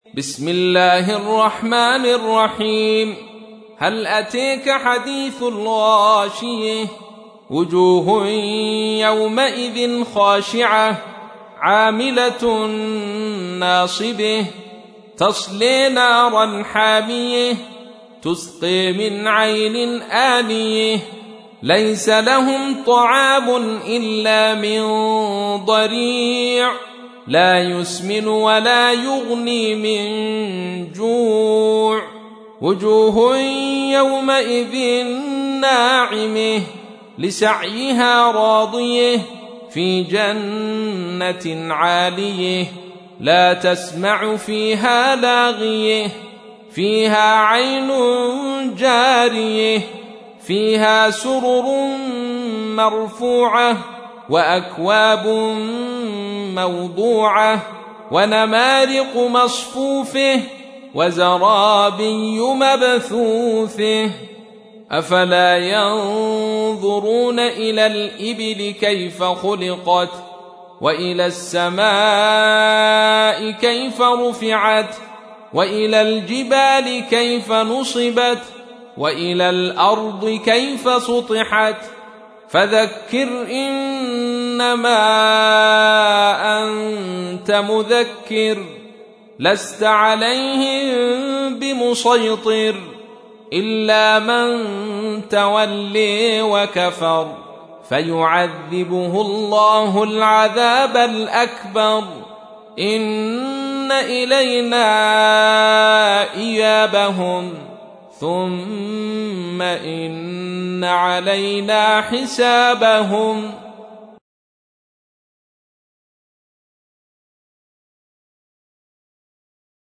تحميل : 88. سورة الغاشية / القارئ عبد الرشيد صوفي / القرآن الكريم / موقع يا حسين